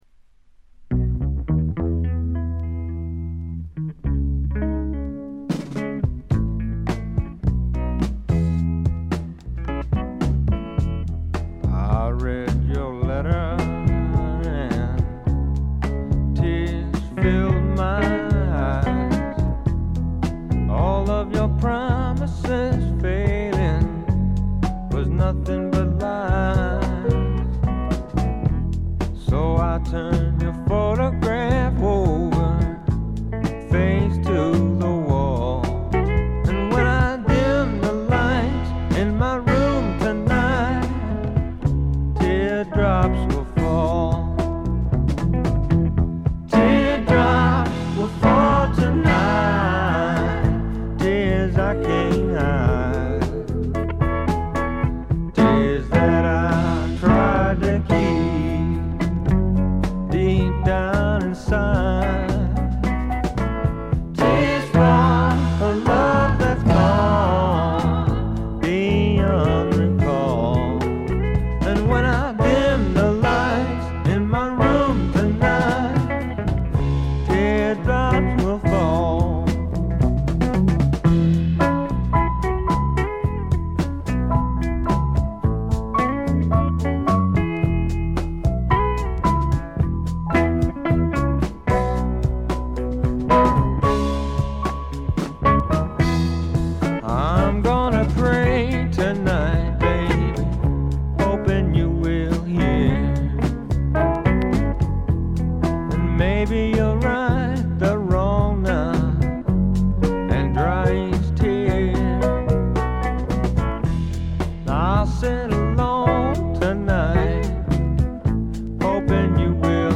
軽微なチリプチがわずかに出る程度。
試聴曲は現品からの取り込み音源です。
guitar, bass, mandolin, vocals